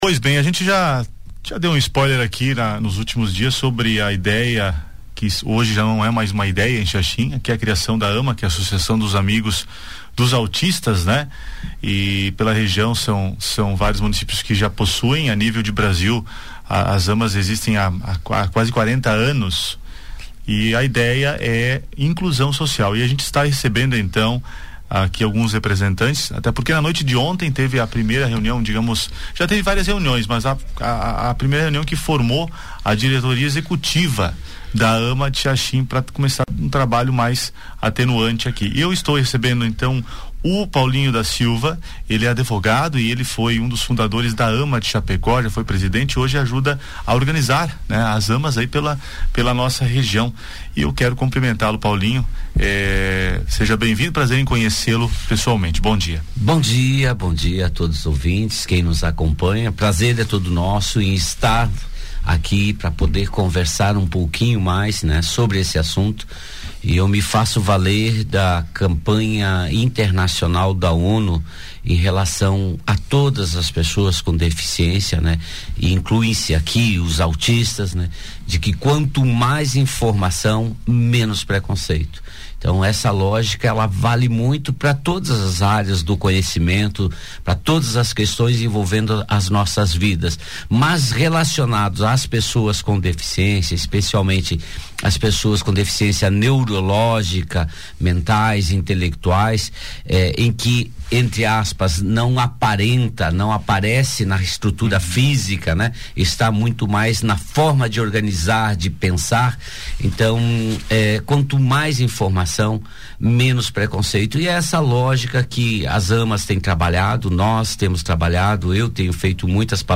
No Conexão Entrevista falamos sobre a consolidação da AMA em Xaxim.